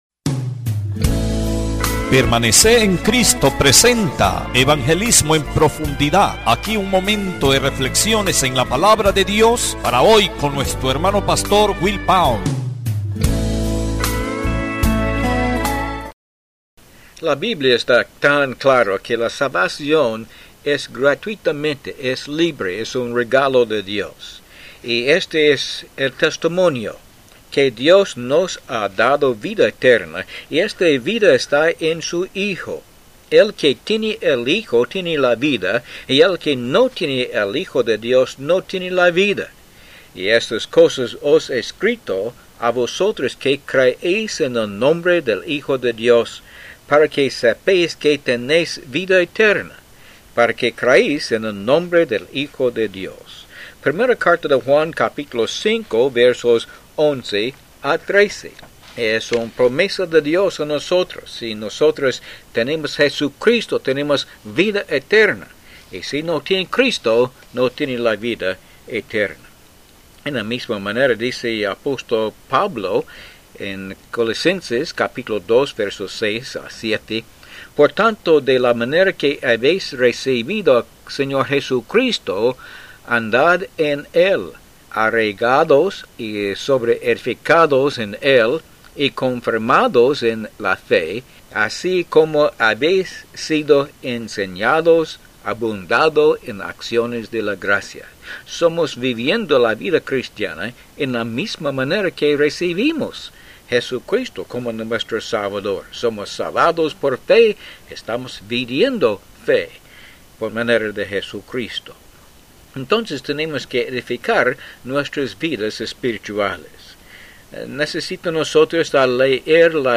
Disfrute estos programas de cinco minutos Cristianos de radio.